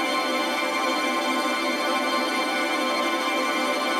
GS_TremString-D7.wav